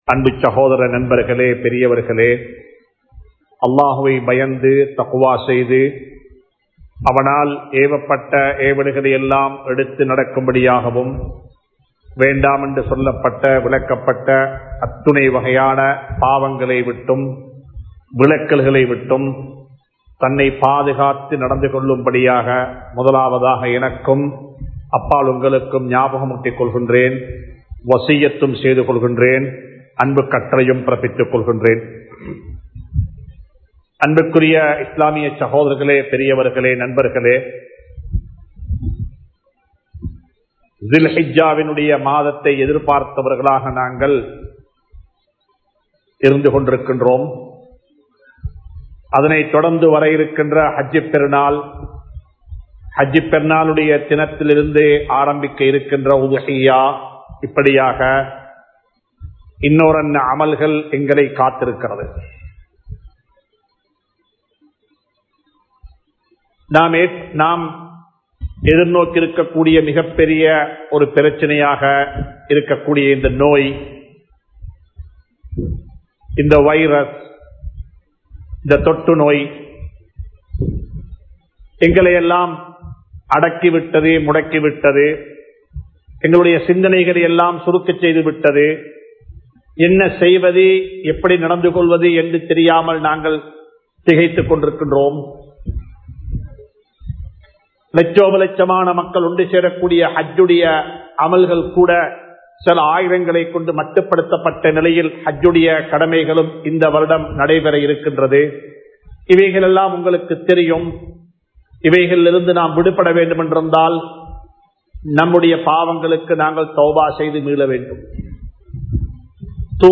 சூரா அல் பஜ்ர் (Surah Al Fajr) | Audio Bayans | All Ceylon Muslim Youth Community | Addalaichenai
Dehiwela, Muhideen (Markaz) Jumua Masjith